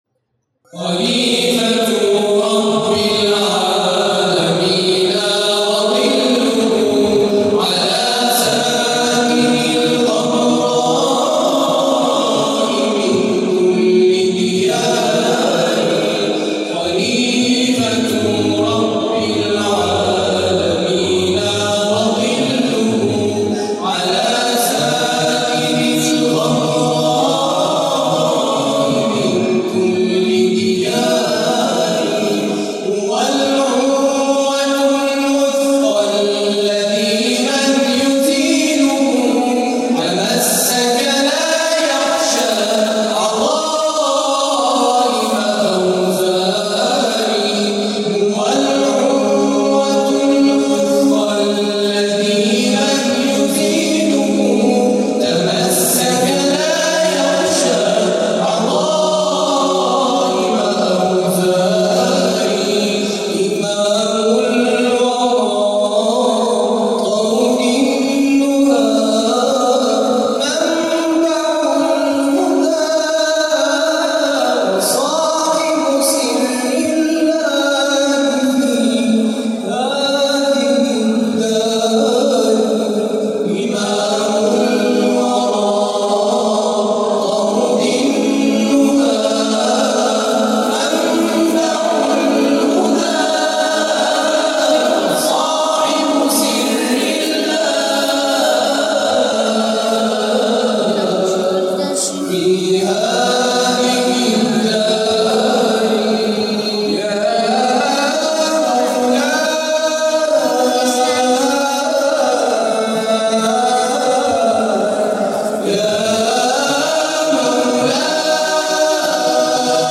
دانلود تواشیح